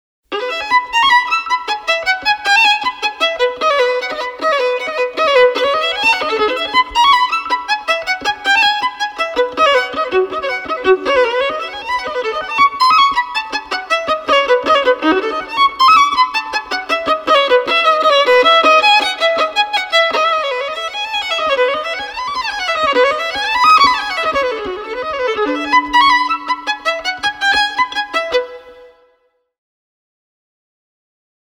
Solo violin tracks recorded at FTM Studio in Denver Colorado
Violin Solo Mendelssohn 3rd mvt.mp3